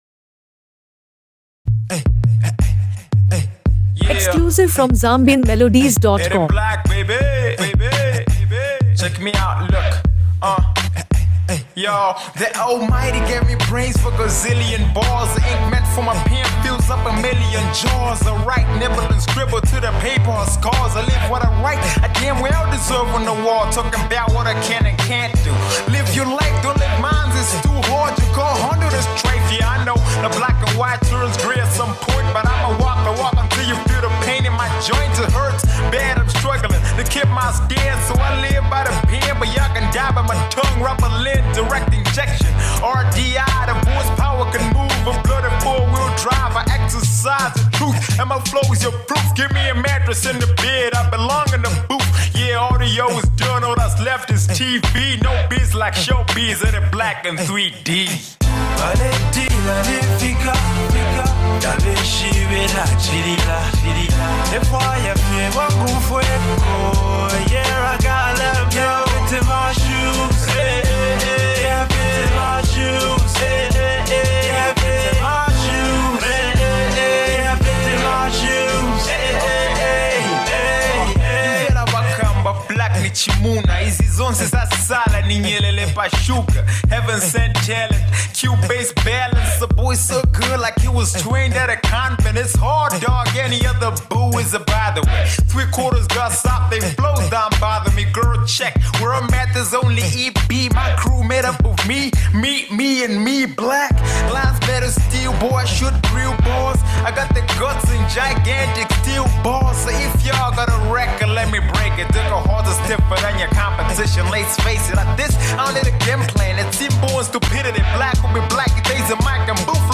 Zambian Music